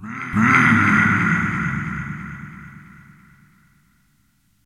PixelPerfectionCE/assets/minecraft/sounds/mob/wither/hurt2.ogg at mc116